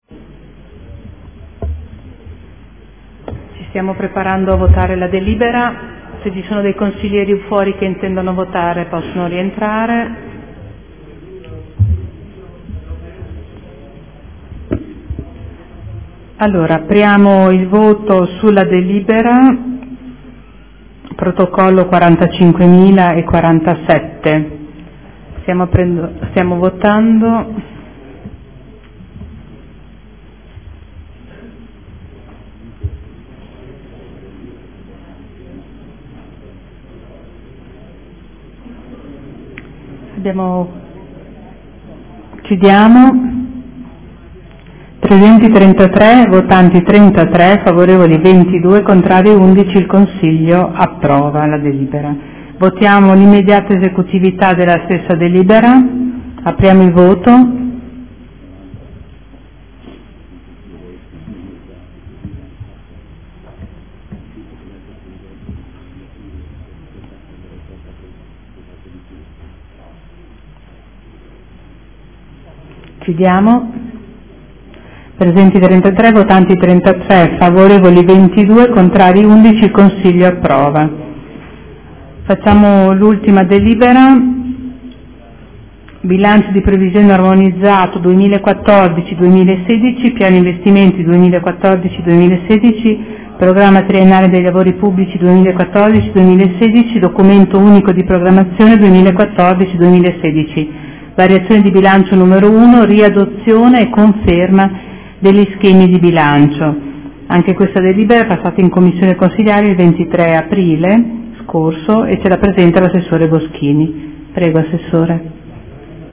Presidente — Sito Audio Consiglio Comunale
Seduta del 28/04/2014.